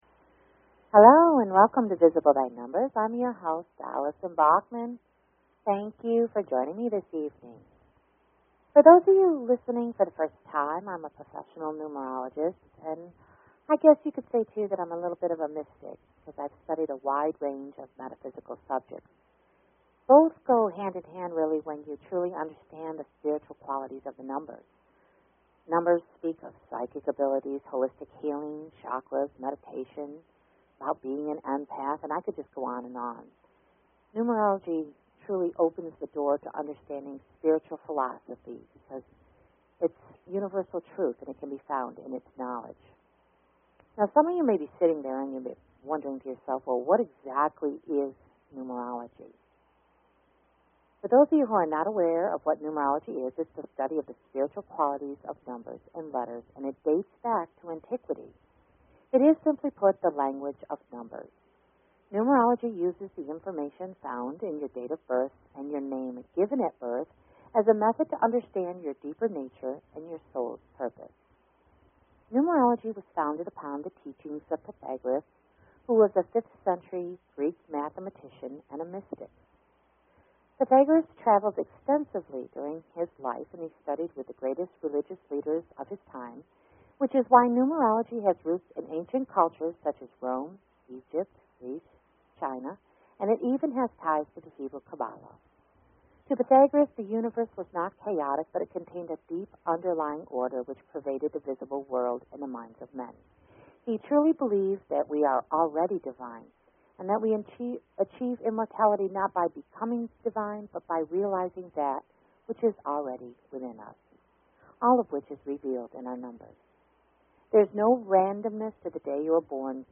Talk Show Episode, Audio Podcast, Visible_By_Numbers and Courtesy of BBS Radio on , show guests , about , categorized as
What is your name telling you? How to find your Expression Number Live on -air readings